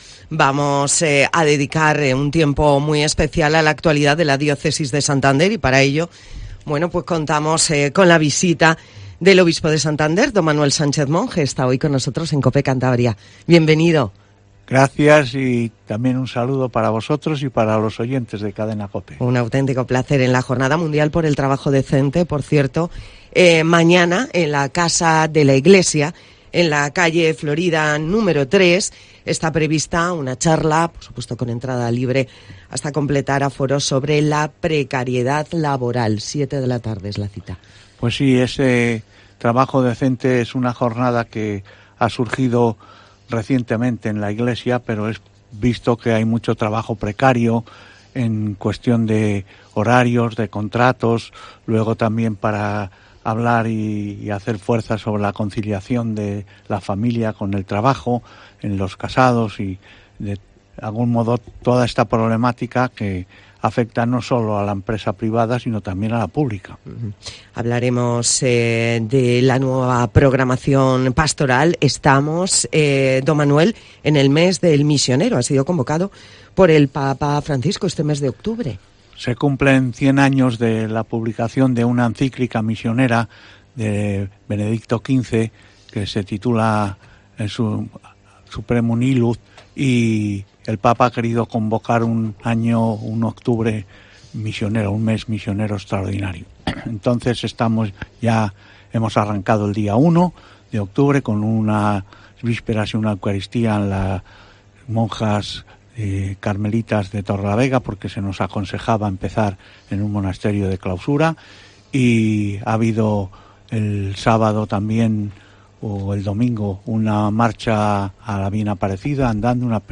El obispo de Santander, Don Manuel Sánchez Monge, encabezará la delegación al Vaticano y dio detalles de este y otros temas en Cope Cantabria
Entrevista a Don Manuel Sánchez Monge en "Herrera en Cope" de Cope + Cantabria